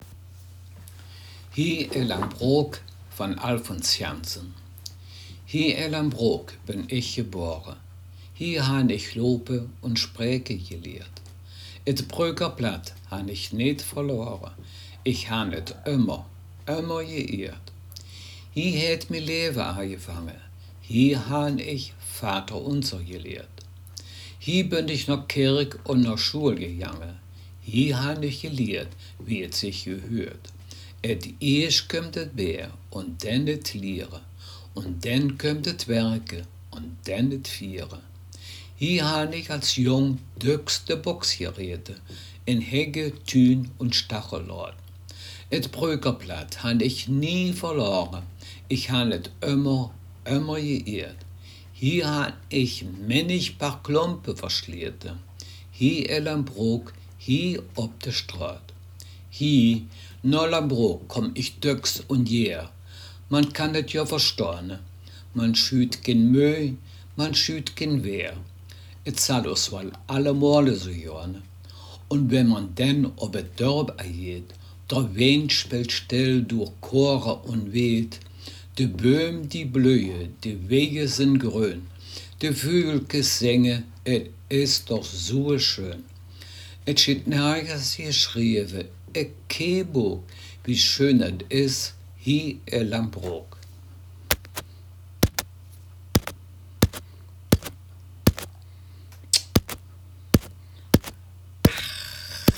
Text Mundart
Gangelter-Waldfeuchter-Platt